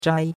zhai1.mp3